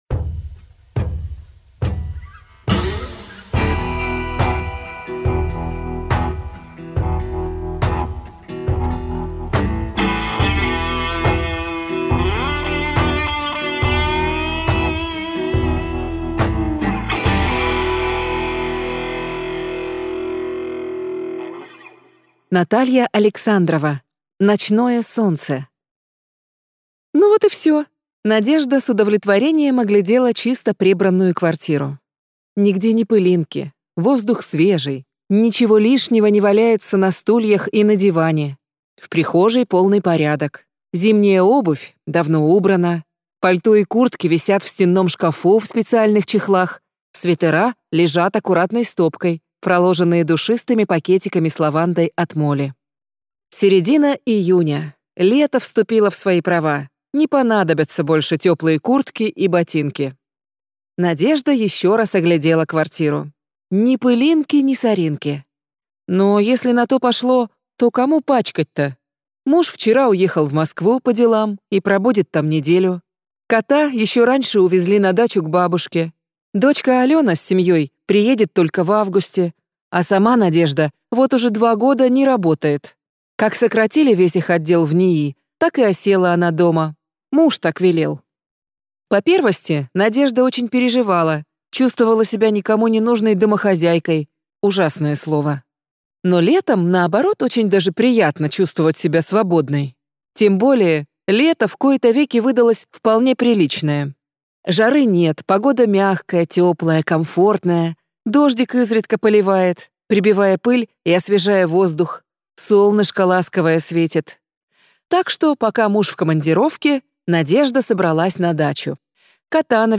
Аудиокнига Ночное солнце - купить, скачать и слушать онлайн | КнигоПоиск